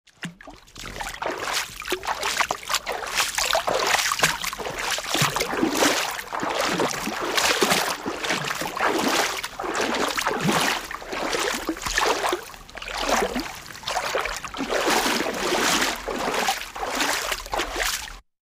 Звуки лужи
Шум ладоней, опускающихся в воду лужи